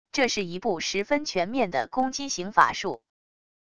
这是一部十分全面的攻击形法术wav音频